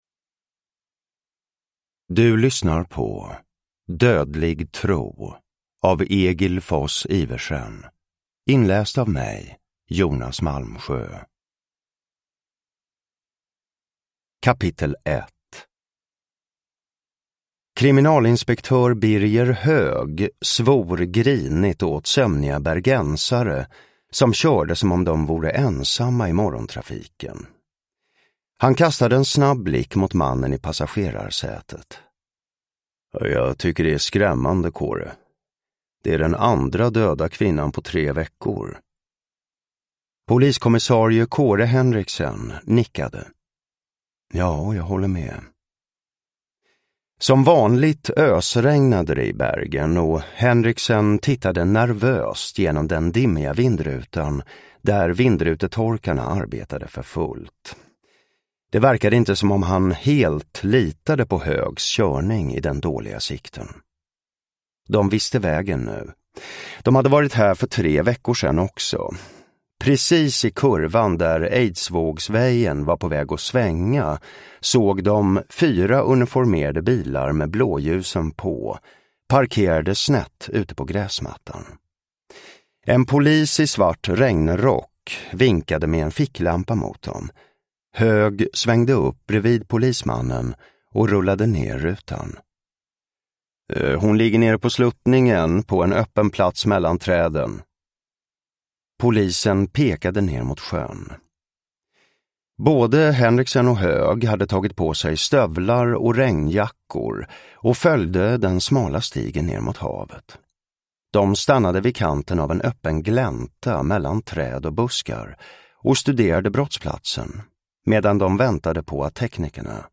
Dödlig tro (ljudbok) av Egil Foss Iversen